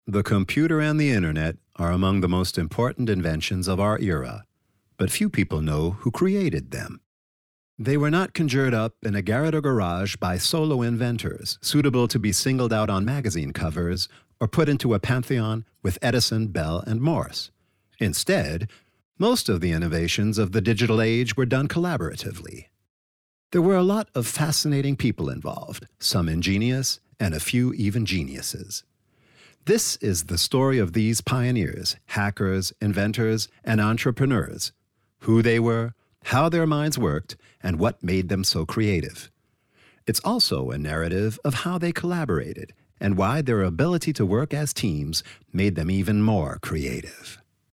US Englisch (Imagefilm) The Innovators